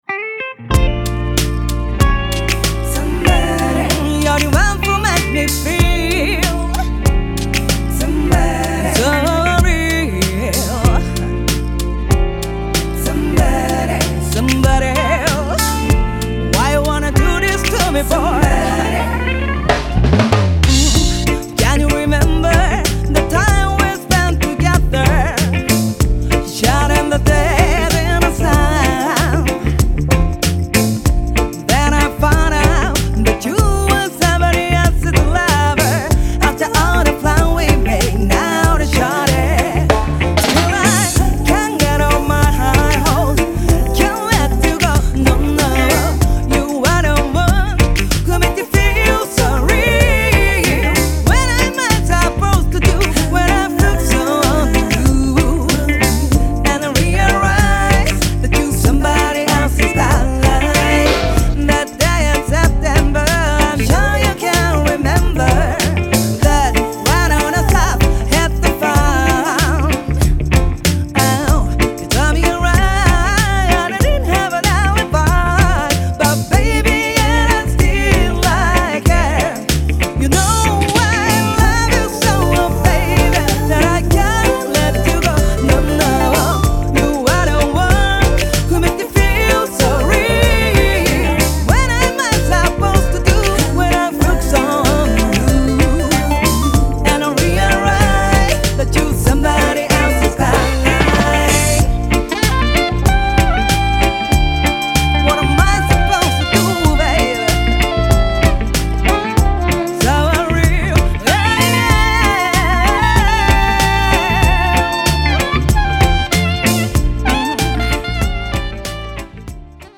ジャンル(スタイル) REGGAE / LOVERS ROCK / SOUL